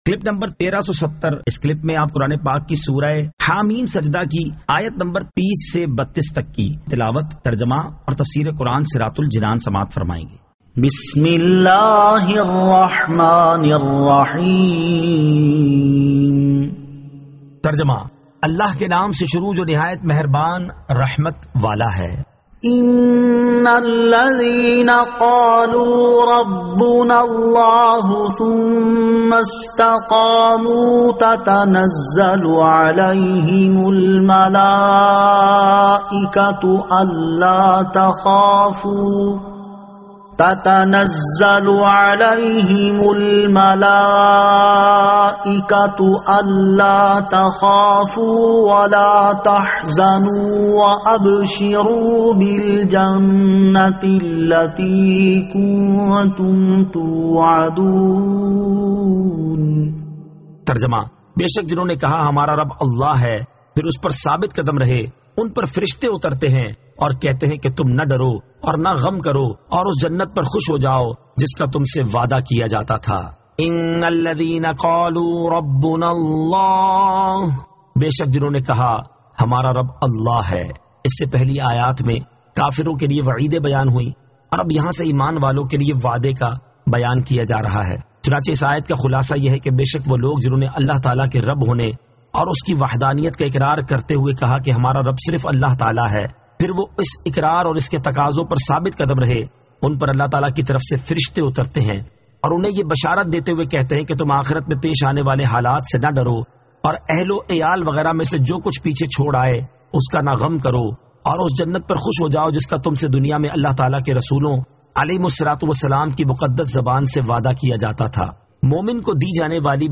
Surah Ha-Meem As-Sajdah 30 To 32 Tilawat , Tarjama , Tafseer